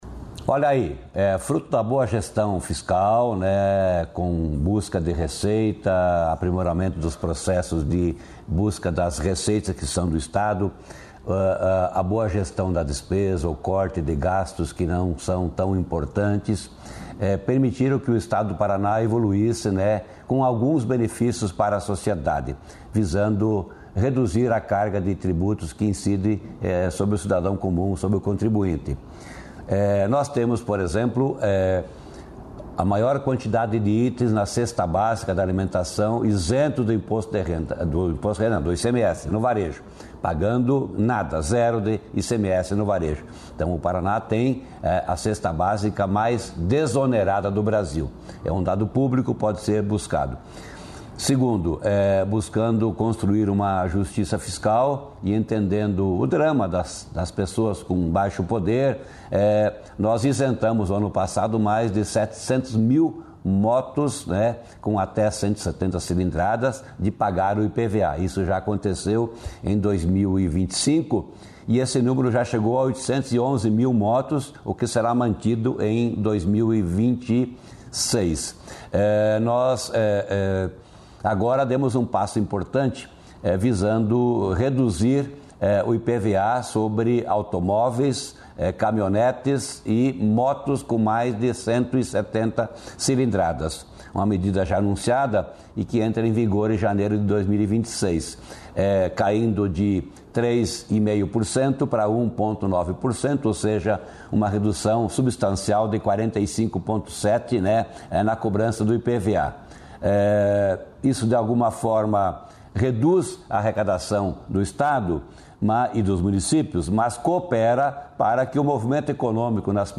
Sonora do secretário da Fazenda, Norberto Ortigara, sobre o bom momento econômico do Estado